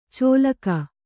♪ cōlaka